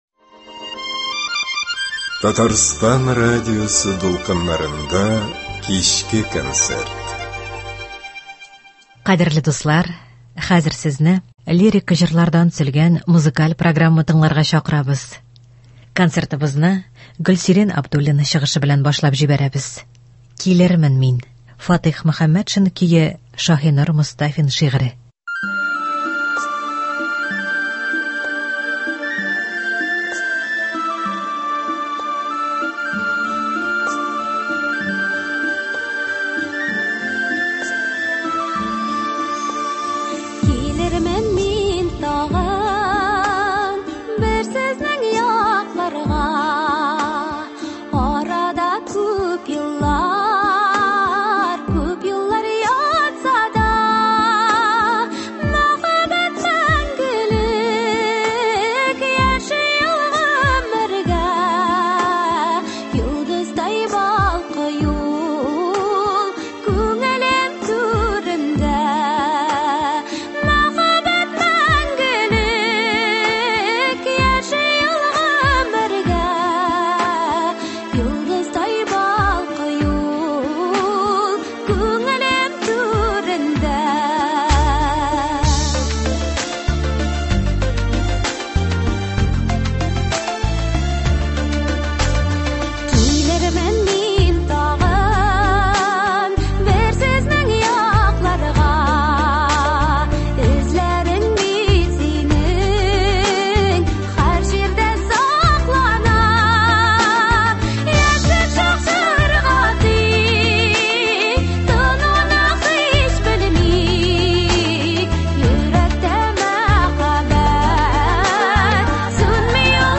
Лирик концерт.